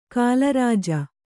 ♪ kālarāja